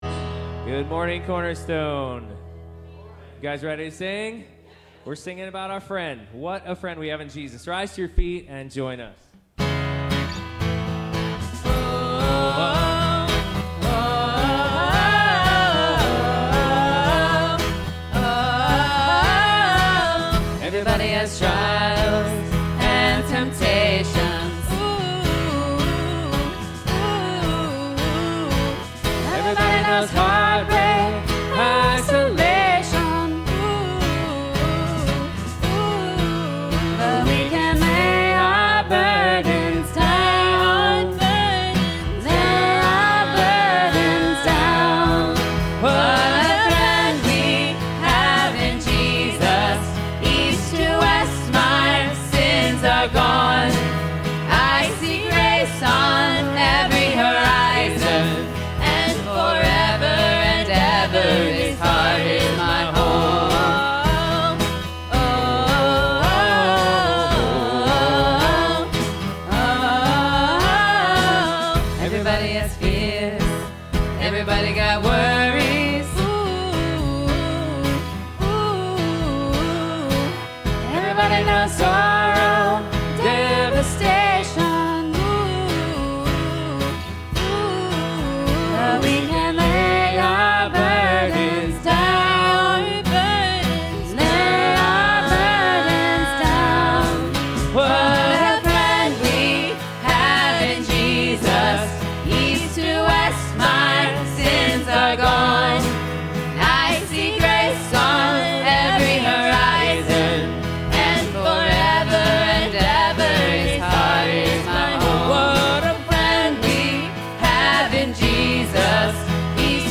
Passage: Esther 6-10 Service Type: Sunday Morning Sermon